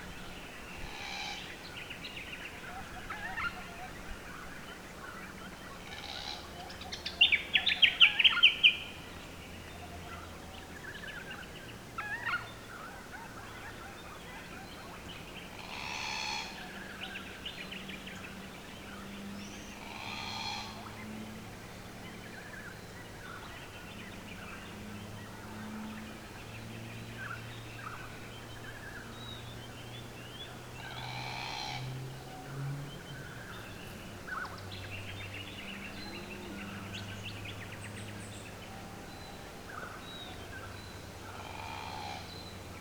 A passive acoustic monitor (PAM) was deployed at the location, and subsequent semi-automated call analysis confirmed the species’ presence from their distinctive calls.
Glossy-black cockatoo call